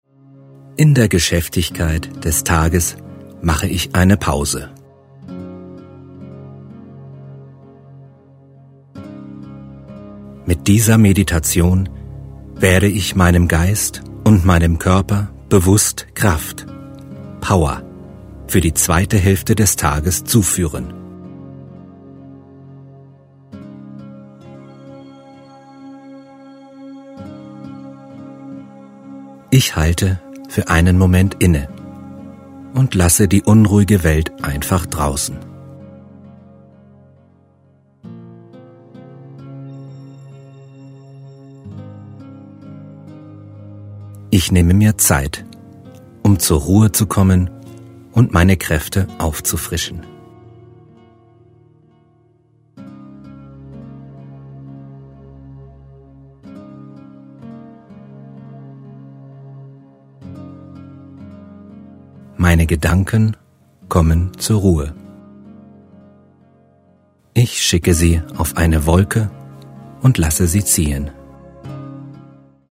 Die begleitende Musik bringt Sie wieder auf Ihre eigene Schwingungsfrequenz. Sie schwingt leicht und wirkt entspannend, belebend und stimuliert den Organismus.
Männliche Stimme   11:06 min